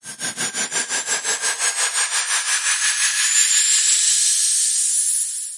标签： 100 bpm Weird Loops Fx Loops 3.23 MB wav Key : Unknown
声道立体声